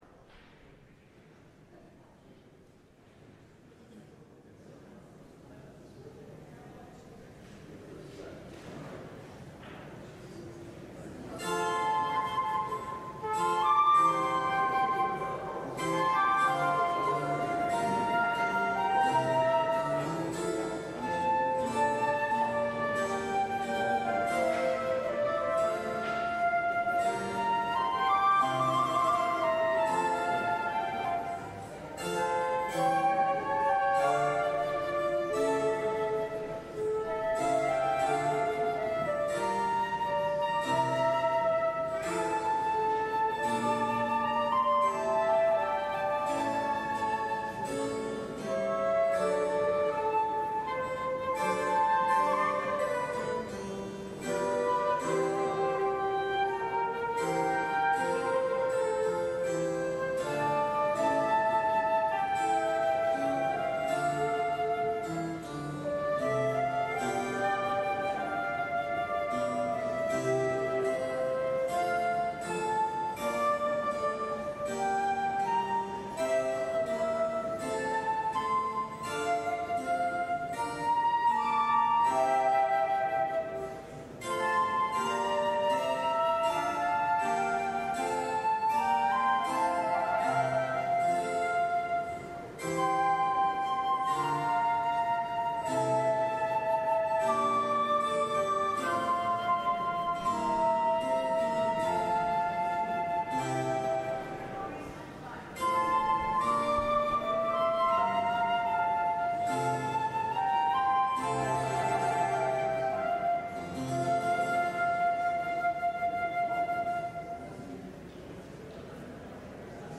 LIVE Morning Worship Service - The Prophets and the Kings: Naboth’s Vineyard